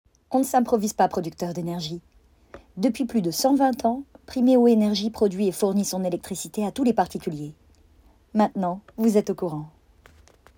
Voix off
25 - 36 ans - Mezzo-soprano Soprano